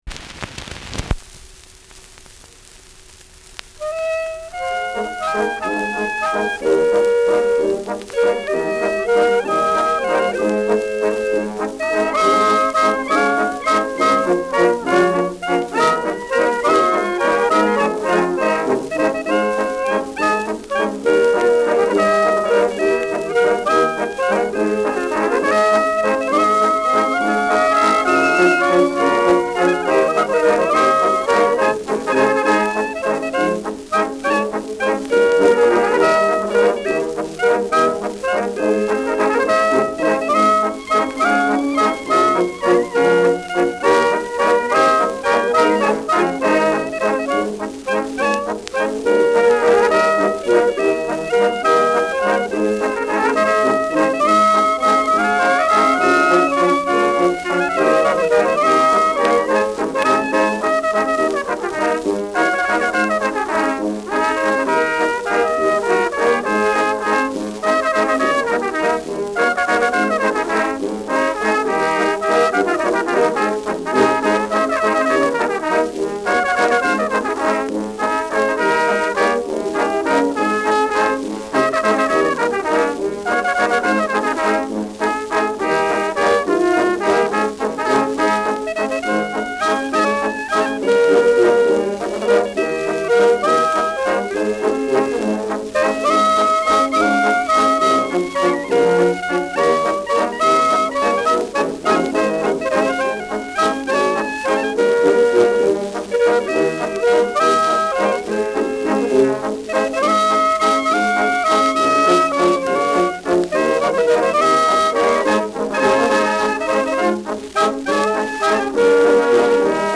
Schellackplatte
Abgespielt : Gelegentlich stärkeres Knacken : Leichtes Leiern : Starkes Störgeräusch in der Mitte
Mit Juchzern.